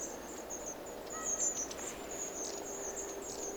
tuollainen laulujoutsenlinnun ääni
tuollainen_laulujoutsenlinnun_aani.mp3